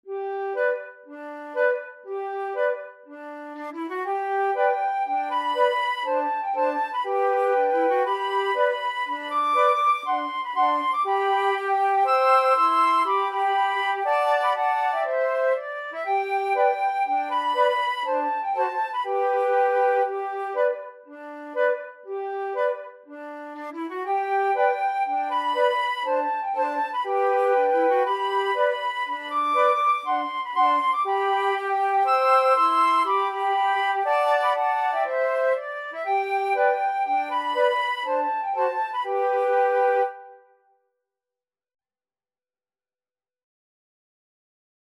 Free Sheet music for Flute Trio
Flute 1Flute 2Flute 3
G major (Sounding Pitch) (View more G major Music for Flute Trio )
Moderately Fast
4/4 (View more 4/4 Music)
Traditional (View more Traditional Flute Trio Music)